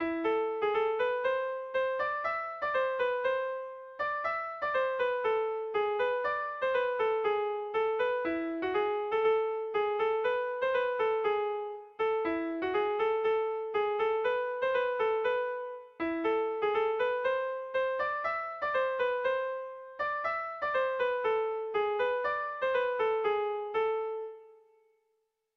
Gabonetakoa
Lehen 2 puntuak errepikatuz amaitzen da.
Zortziko txikia (hg) / Lau puntuko txikia (ip)
ABD1D2